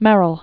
(mĕrəl), James Ingram 1926-1995.